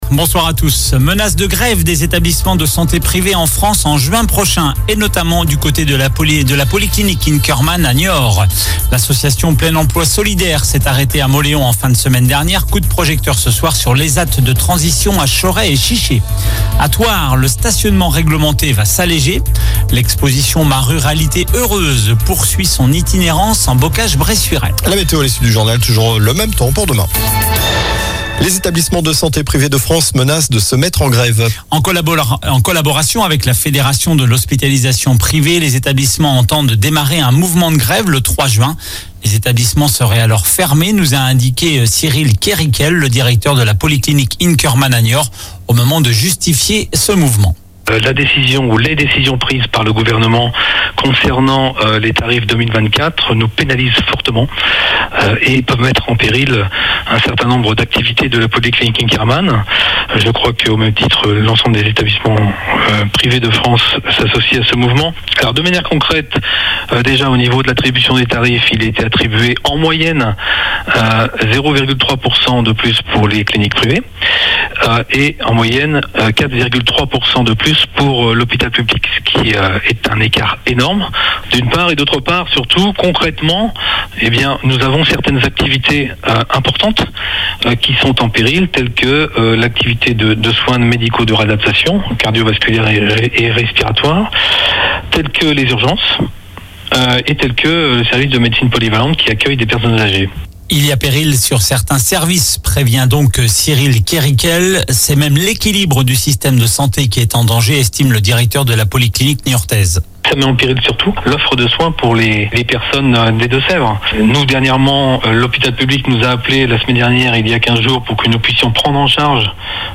infos locales